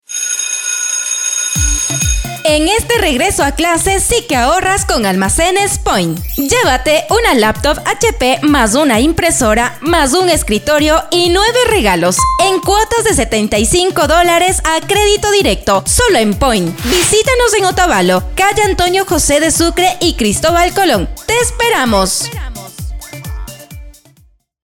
Grabación y Producción de cuñas | Radio Ilumán
En Radio Ilumán te ofrecemos el servicio profesional de grabación de cuñas radiales en dos idiomas: kichwa y castellano, con locutores y locutoras que comunican con autenticidad, claridad y cercanía a la audiencia.